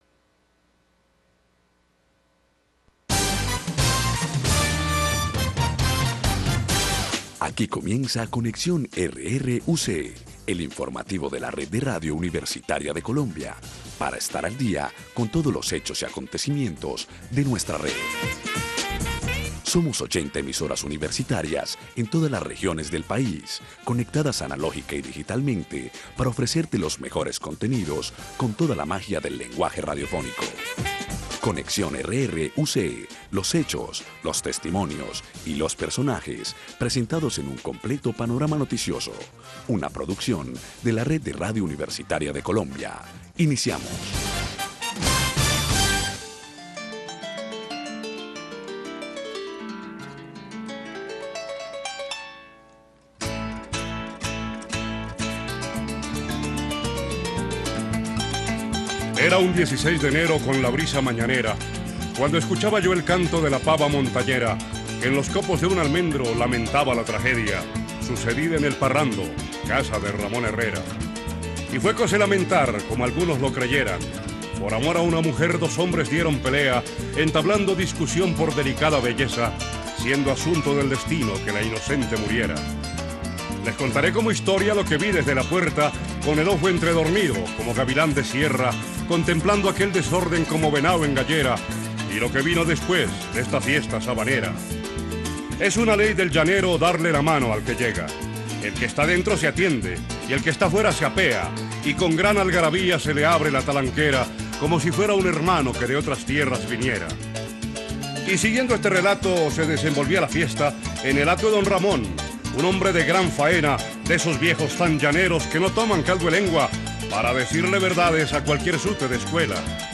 Conexión RRUC #31 – Feria del Libro, Entrevista a Mario Mendoza RUV – RUV – RADIO UNAD VIRTUAL – EMISORA DE LA UNIVERSIDAD NACIONAL ABIERTA Y A DISTANCIA – UNAD